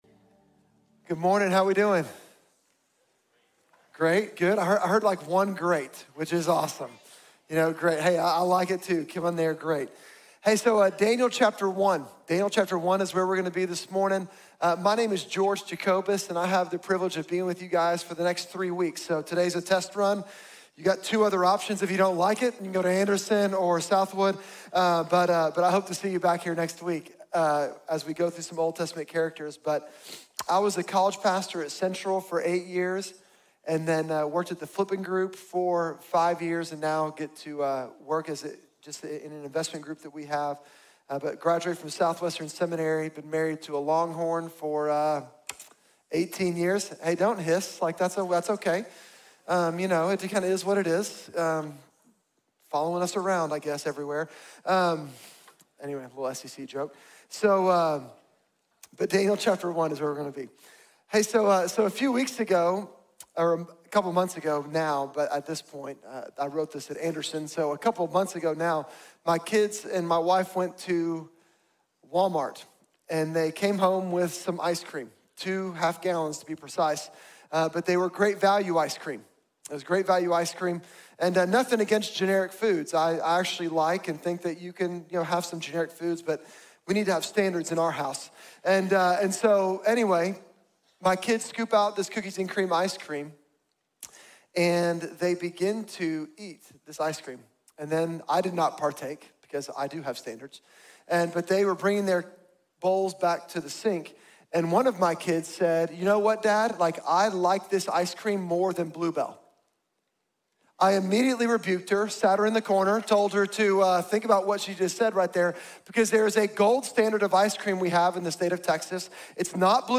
Wisdom beyond our years | Sermon | Grace Bible Church